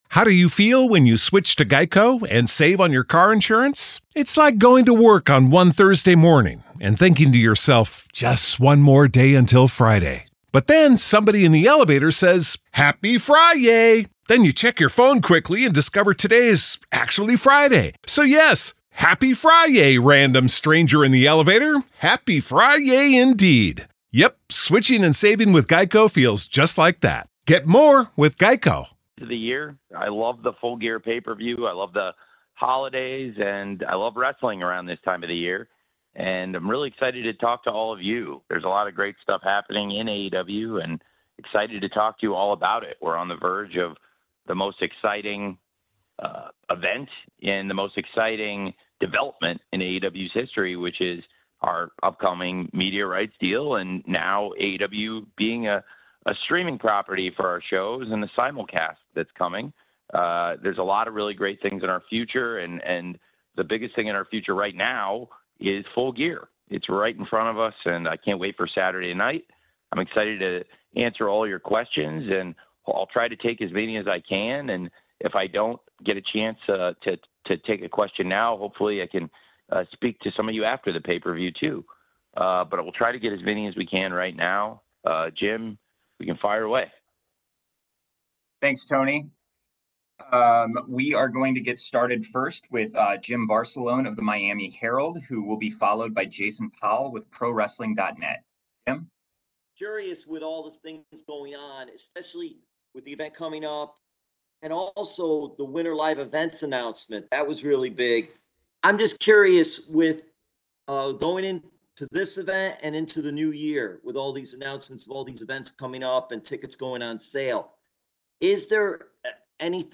Tony Khan: Kenny Omega Return, Toni Storm "Retirement," AEW WBD Max Deal | AEW Full Gear 2024 Media Call | Fightful News
Tony Khan speaks to media ahead of AEW Full Gear